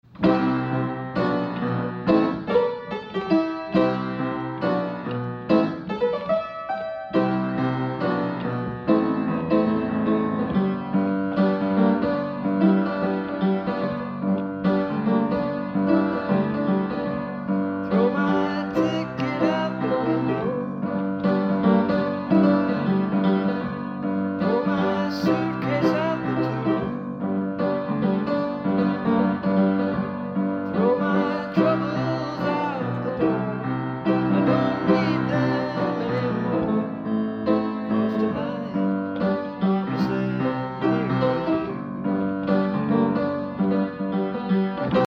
not much of a piano player but I love the intro part.